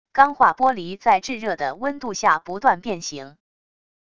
钢化玻璃在炙热的温度下不断变形wav下载